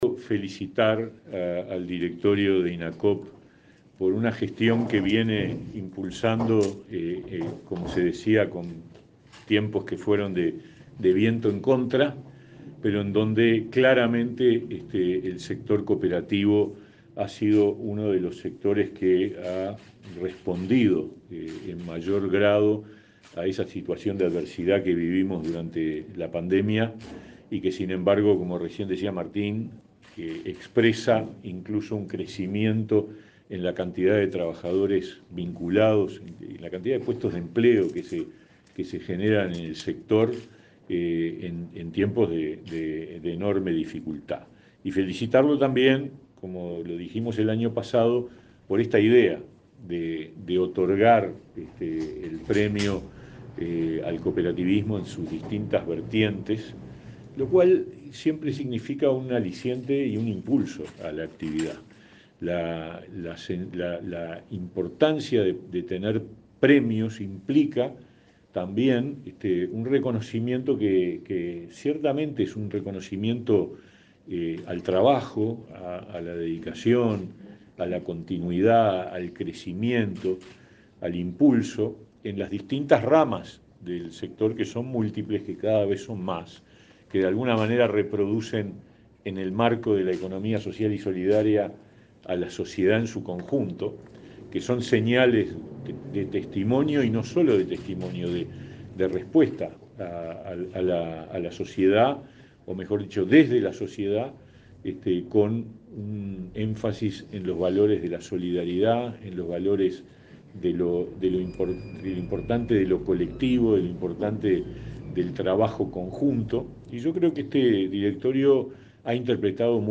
Palabras del ministro de Trabajo, Pablo Mieres
El ministro de Trabajo y Seguridad Social, Pablo Mieres, participó este jueves 5 en Montevideo del lanzamiento de la segunda edición del premio Miguel